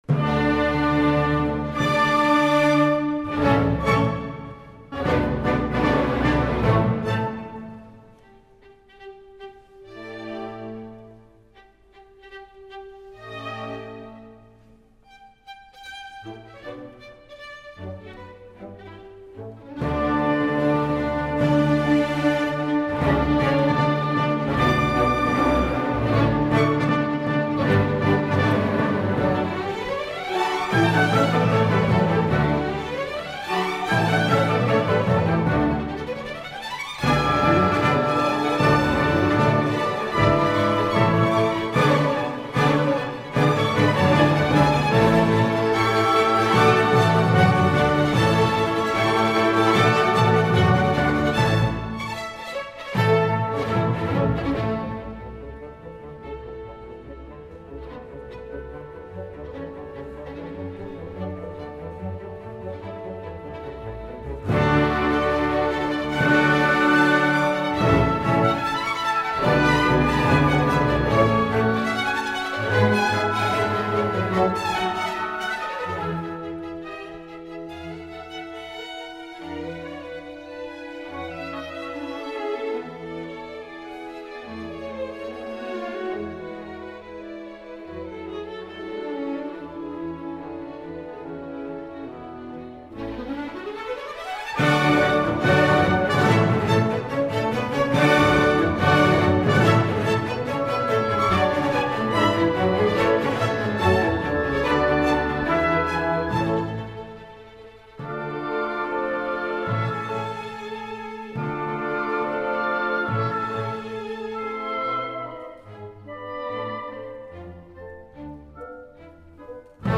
Nel Ridotto dei palchi Arturo Toscanini, del Teatro alla Scala